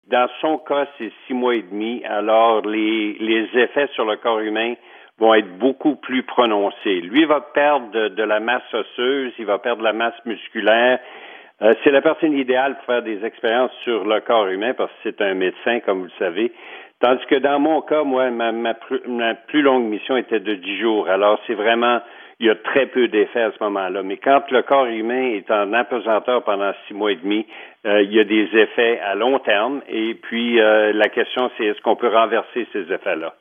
Alain Gravel entrevistó a Marc Garneau.